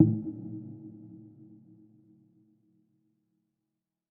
week7-button.mp3